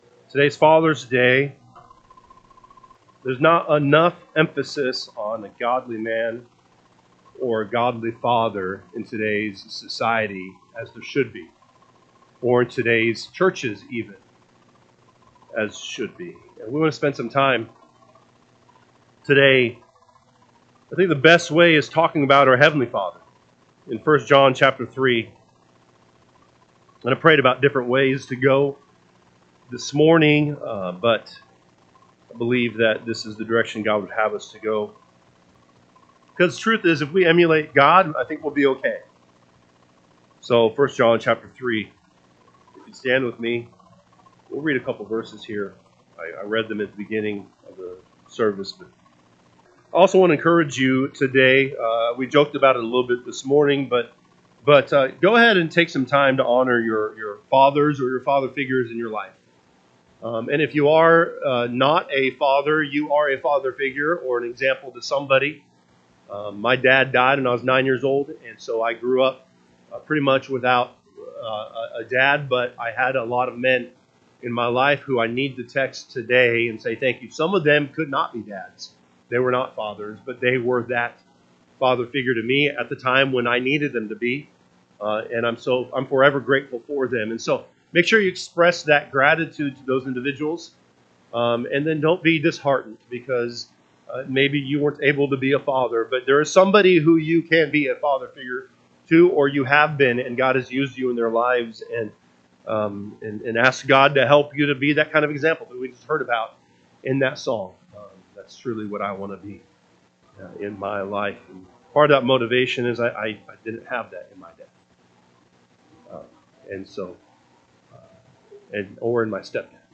June 15, 2025 am Service 1 John 3:1-2 (KJB) 3 Behold, what manner of love the Father hath bestowed upon us, that we should be called the sons of God: therefore the world knoweth us not, becaus…
Sunday AM Message